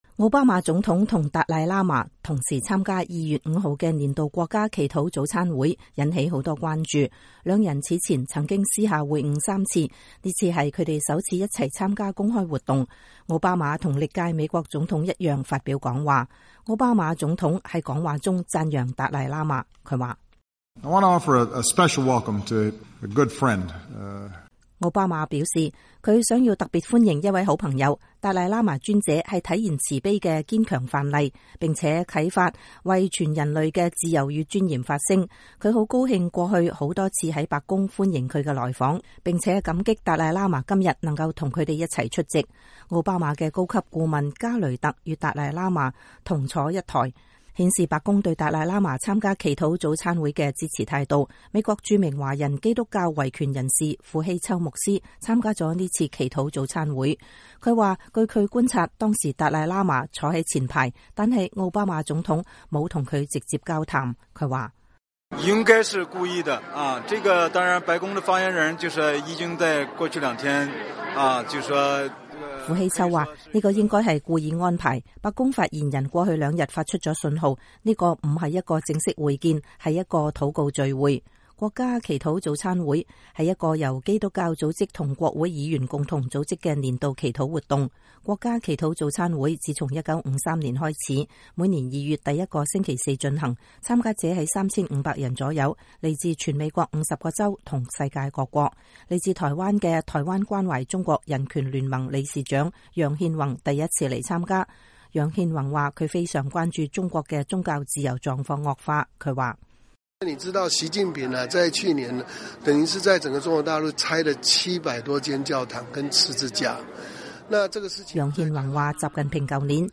美國總統奧巴馬在國家祈禱早餐會發表講話